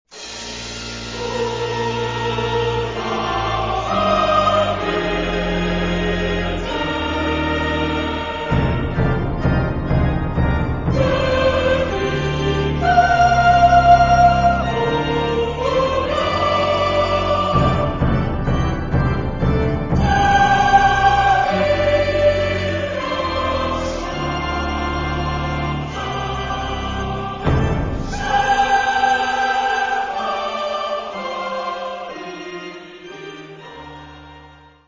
Genre-Style-Forme : Sacré ; contemporain ; Psaume
Type de choeur : SATB  (4 voix mixtes )
Solistes : Soprano (1)  (1 soliste(s))
Instrumentation : Orchestre